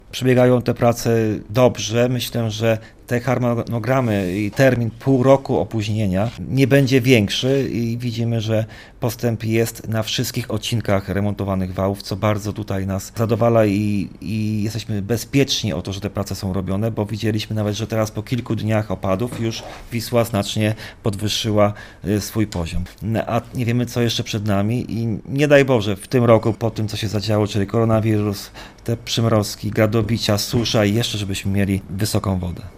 Obecnie prace trwają bez zakłóceń – mówi starosta sandomierski Marcin Piwnik: